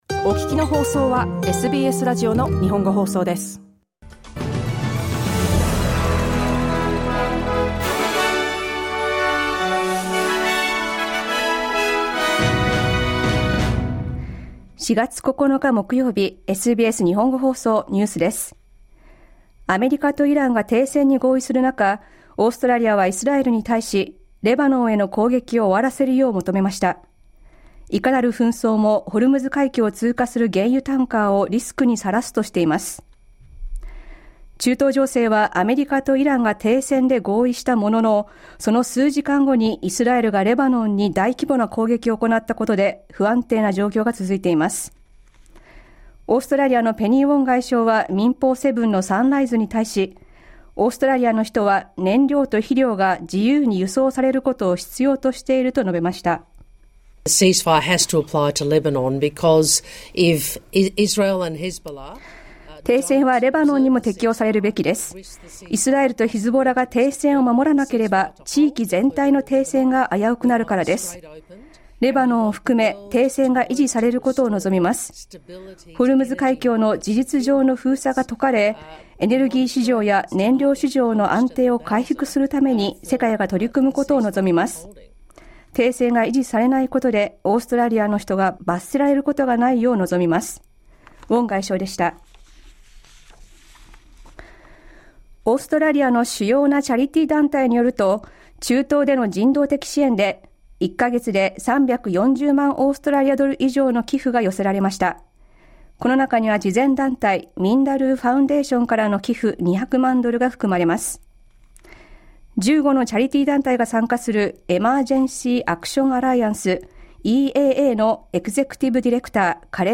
Motorists may have to wait months for fuel prices to ease, despite a brief pause in fighting between the US and Iran. New South Wales Premier Chris Minns says the expansion of the Metro in Sydney to include coverage between Westmead and the Sydney CBD will support new housing projects. News from today's live program (1-2pm).